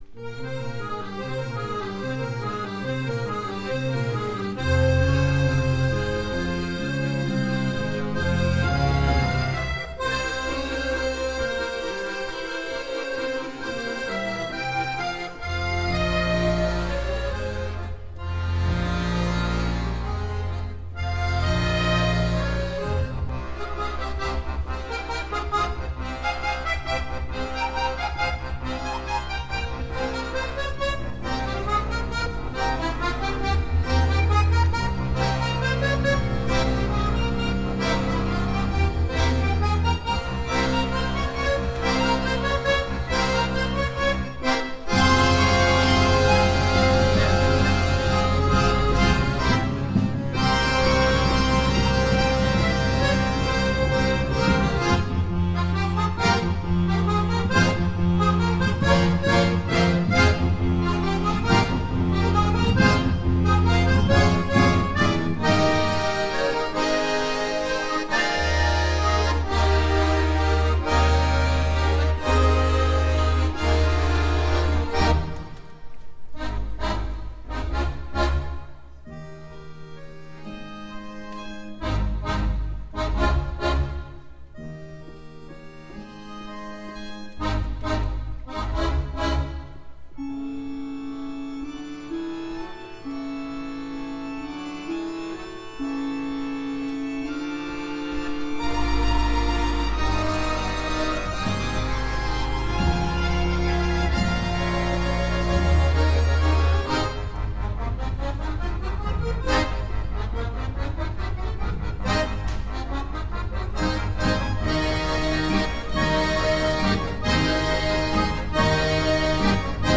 2014 – Akkordeonorchester Neustadt bei Coburg e. V.